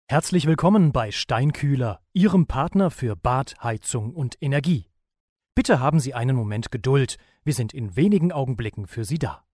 Imagefilm